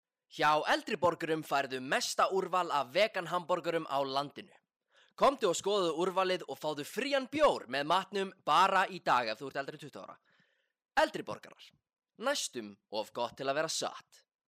Raddprufur